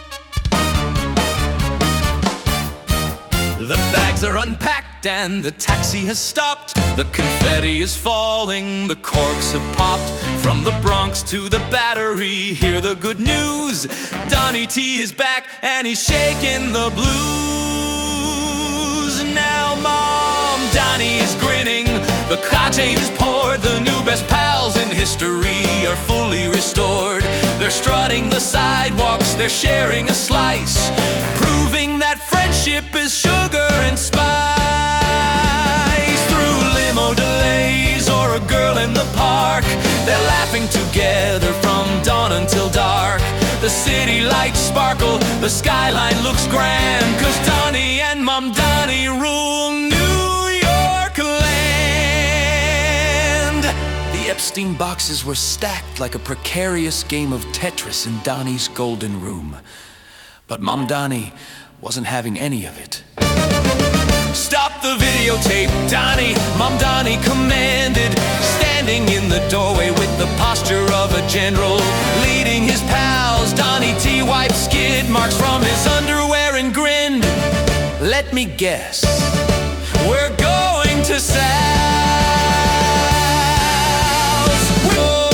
End of Show Mixes: